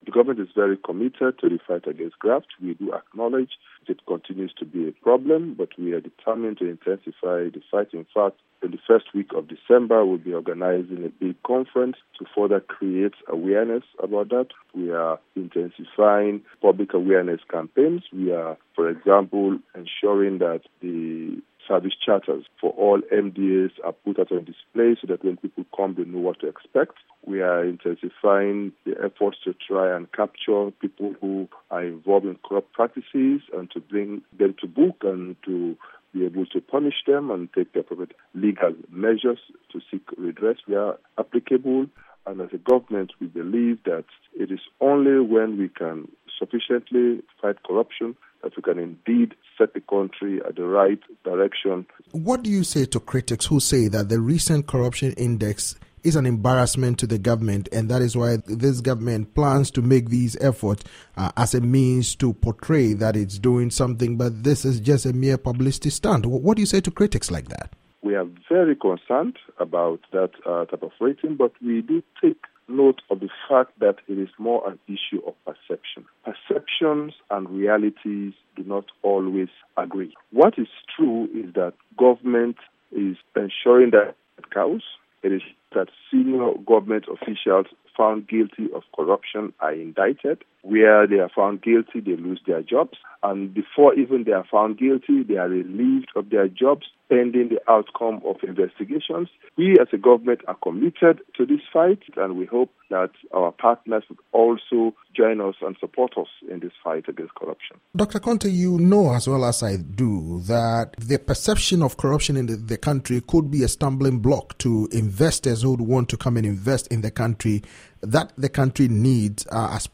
President Koroma's chief of staff talks about graft